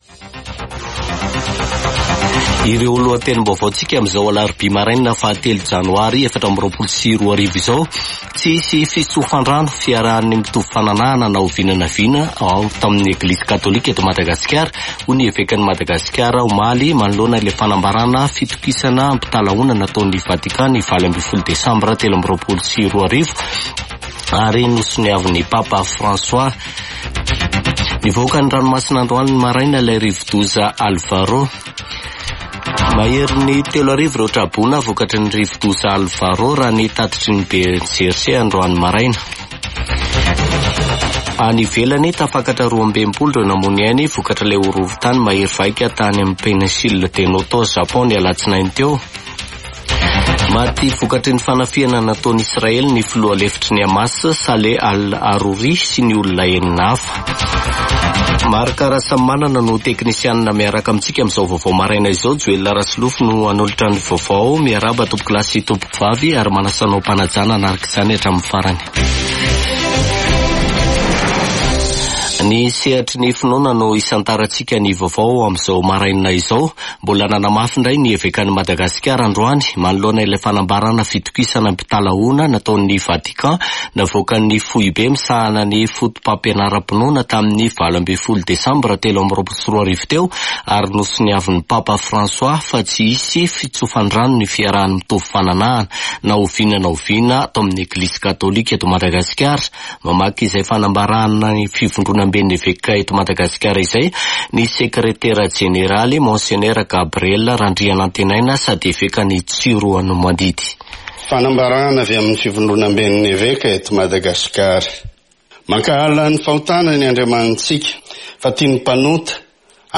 [Vaovao maraina] Alarobia 3 janoary 2024